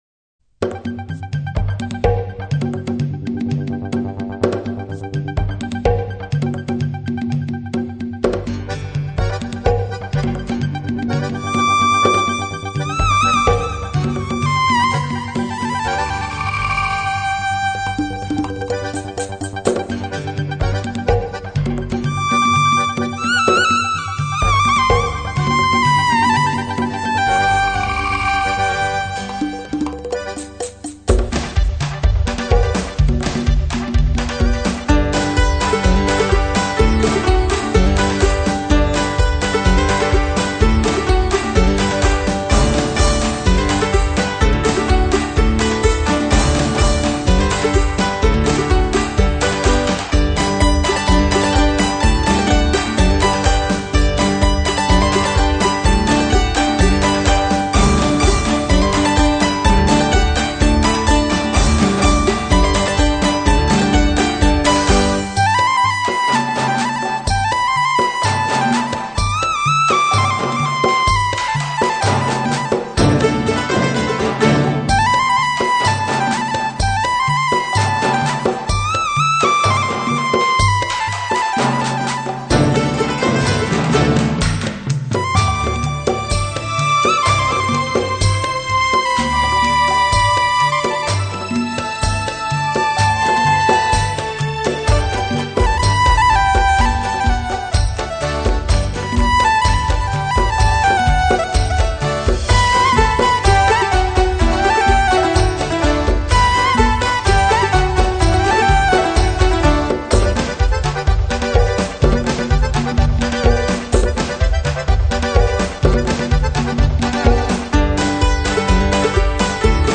哀牢山上的哈尼人，則以獨特的八聲部混聲合唱與報早春的布穀鳥相應和著，